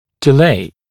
[dɪ’leɪ][ди’лэй]откладывать, замедлять; задержка